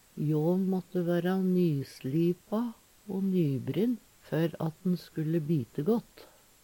DIALEKTORDET
Nokon seier "sLipa"